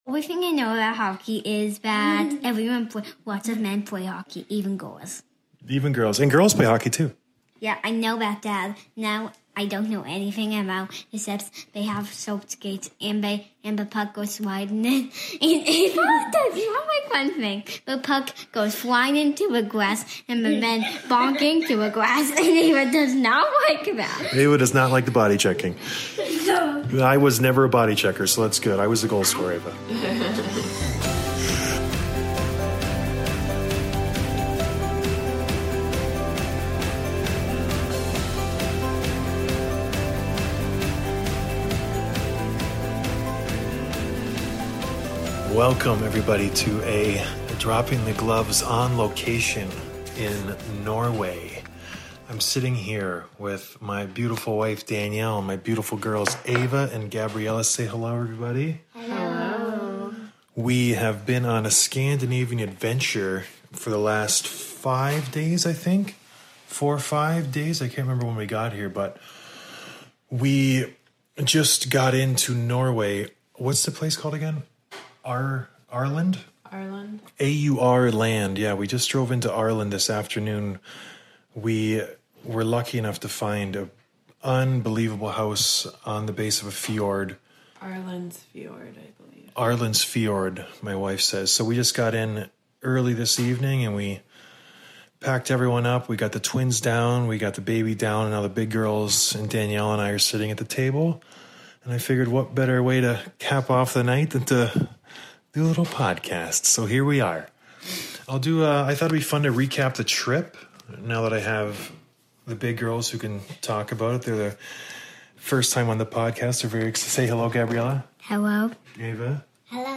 I am not in the studio, but I still wanted to get an episode out. Please excuse the audio quality (I don't think it's all that bad)...